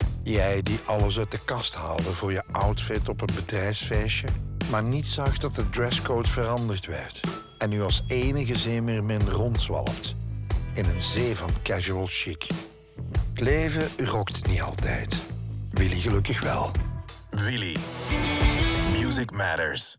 De audio in de geluidsstudio’s van DPG Media zelf
Radiospot Radio Willy Dresscode.wav